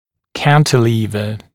[‘kæntɪliːvə][‘кэнтили:вэ]консоль, кронштейн, рычаг (используется для перемещения отдельных зубов)